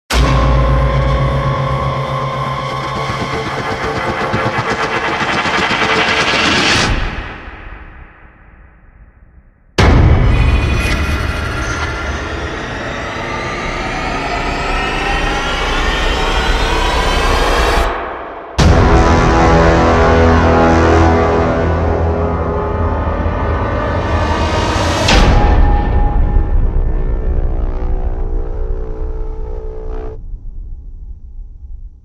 Inception Sound effects Riser and sound effects free download
Inception Sound effects Riser and Hits